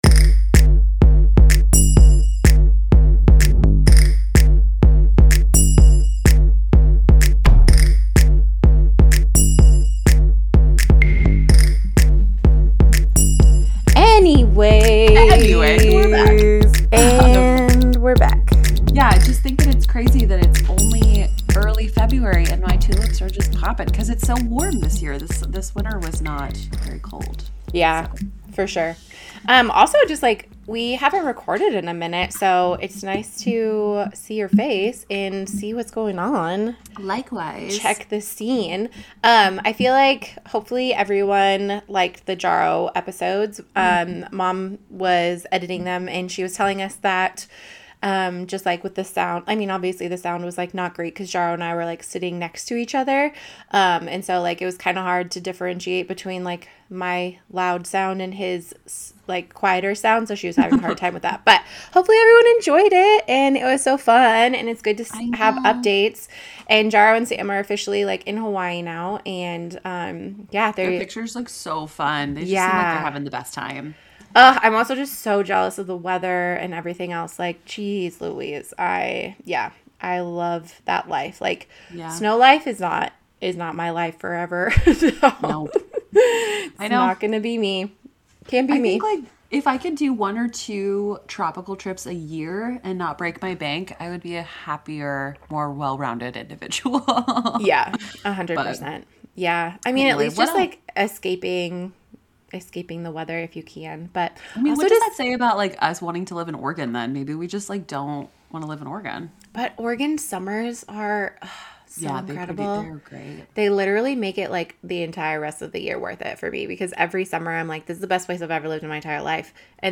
The ladies discuss various current topics.